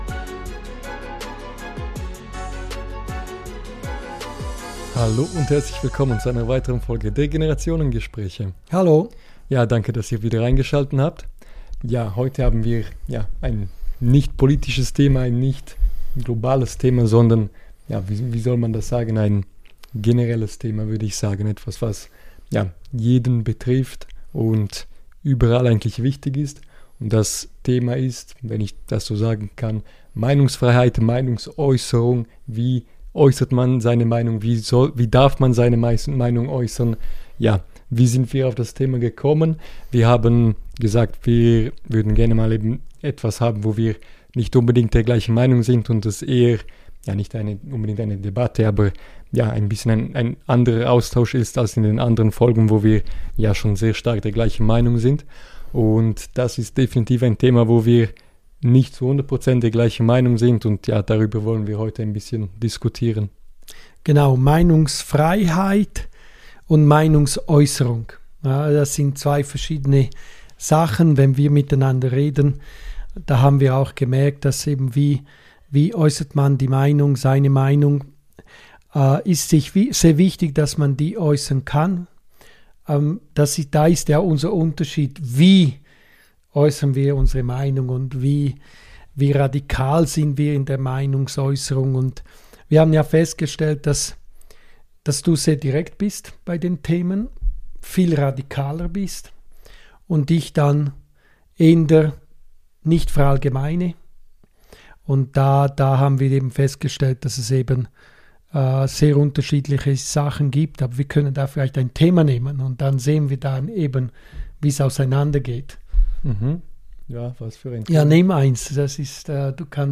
Die Wahrheit sagen – egal was es kostet - Vater & Sohn:Generationengespräche #25 ~ Vater & Sohn: Generationengespräche Podcast